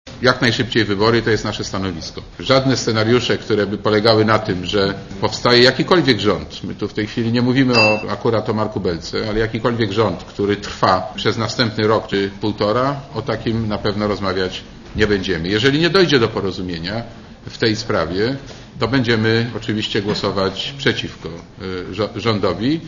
Mówi lider SdPl, Marek Borowski